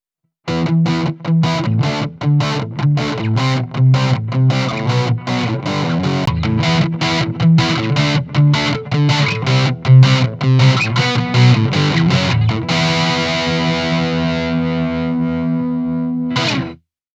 Proviamo a gestire il contenuto in frequenza di alcune tracce registrate con microfono Audio Technica 4040 e preamplificatori API 512C (chitarre acustiche), Neve 1073 (chitarre elettriche) e Focusrite ISA One (voce).
Nelle clip di esempio si parte con il suono non equalizzato, l’effetto viene inserito successivamente per lasciar apprezzare le differenze.
ElectricGtr Dist 2
ElectricGtr-Dist-2.wav